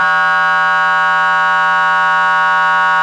Index of /server/sound/emv/sirens/federal signal pa 300
emv_horn.wav